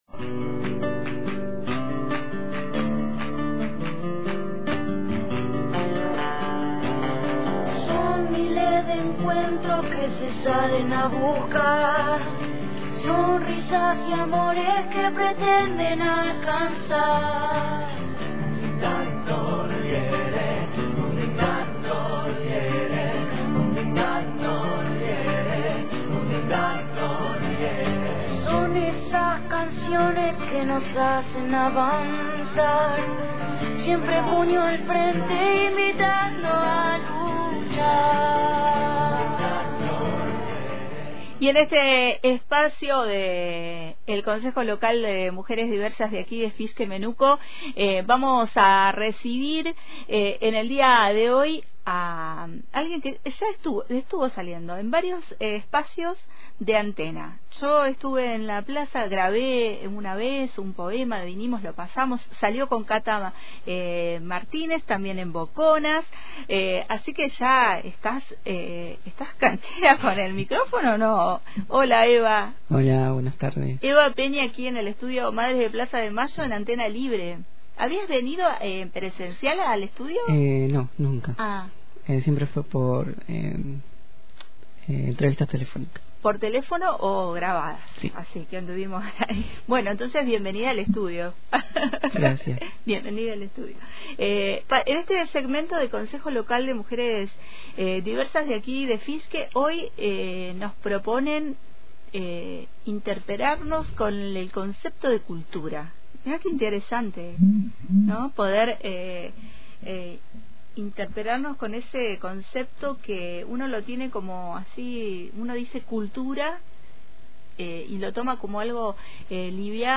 En el micro del Consejo Local de Mujeres Diversas, recibimos en el estudio «Madres de Plaza de mayo»